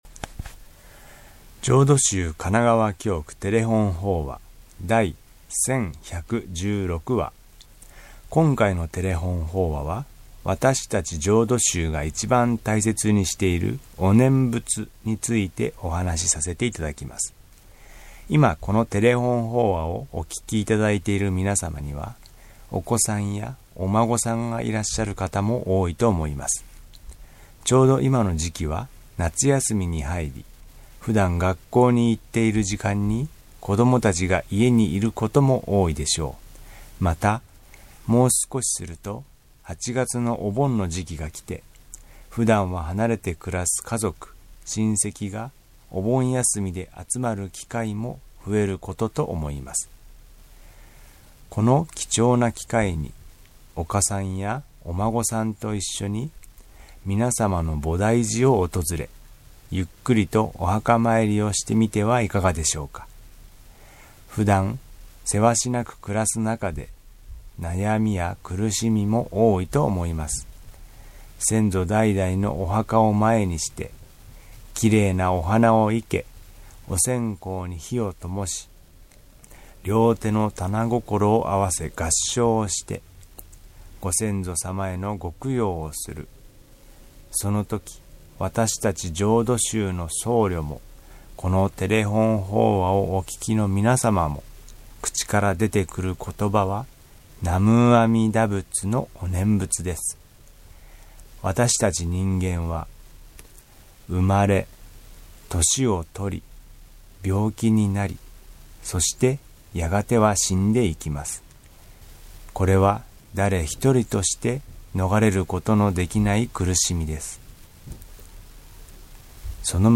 テレホン法話
法話 #1116